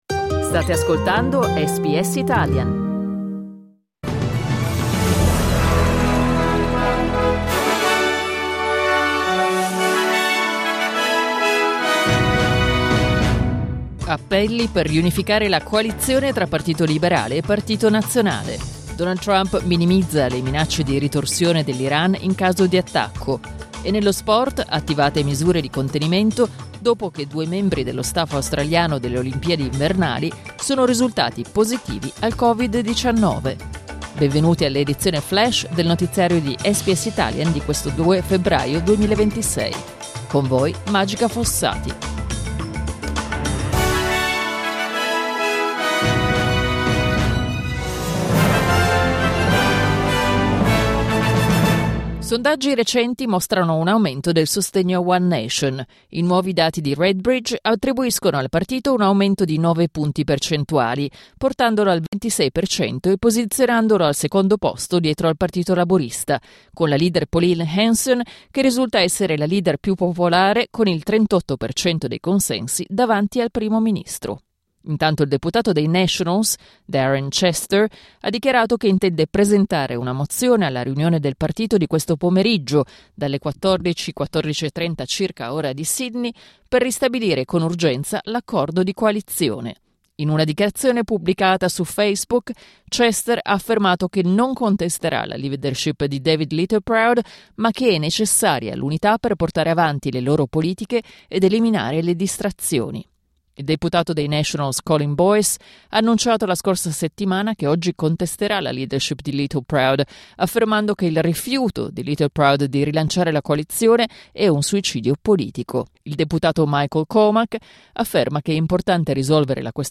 News flash lunedì 2 febbraio 2026
Appelli per riunificare la coalizione tra partito liberale e partito nazionale, mentre Donald Trump minimizza le minacce di ritorsione dell'Iran in caso di attacco. L’aggiornamento delle notizie di SBS Italian.